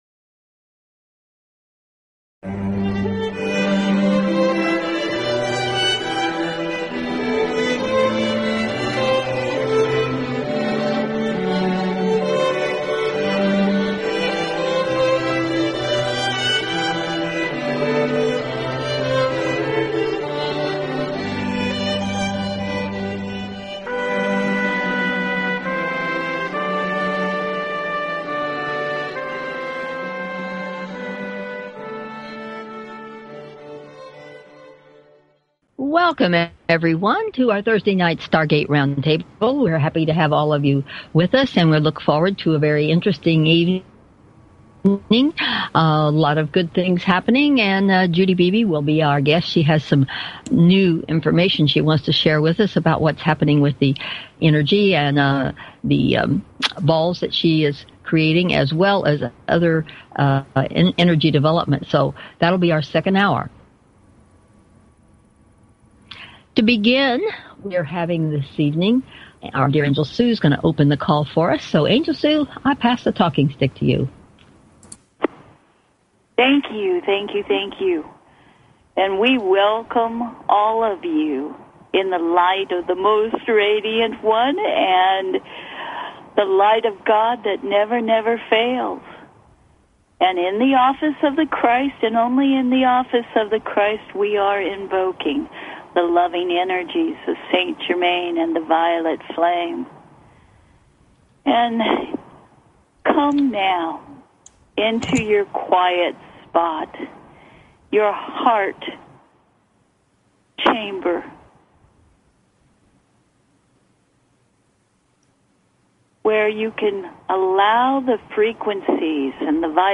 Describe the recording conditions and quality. After an opening meditation, we begin with a brief overview of "Breaking News" and also offer a segment for you to get your questions answered.